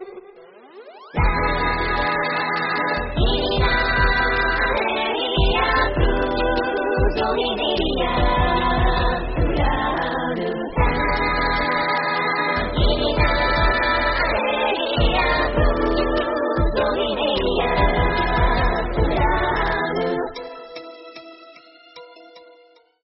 enna-burn-chant.mp3